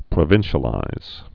(prə-vĭnshə-līz)